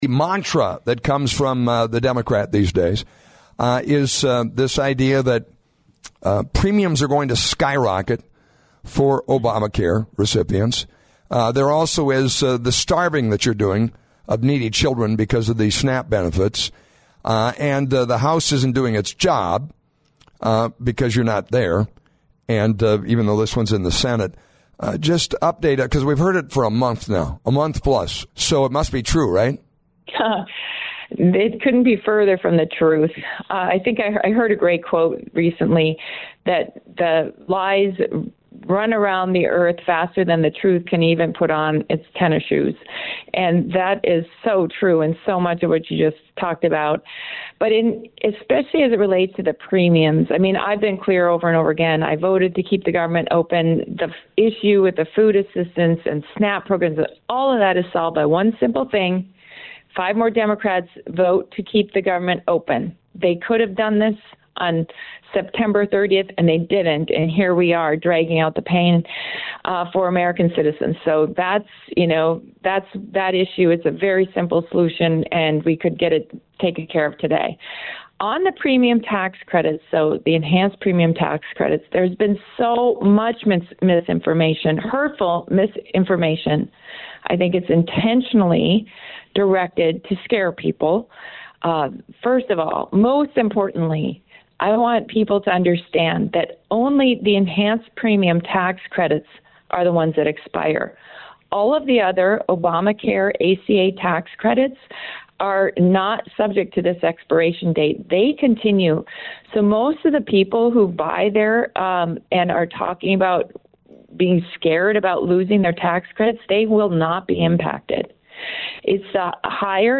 11-3-fedorchak-full-interview.mp3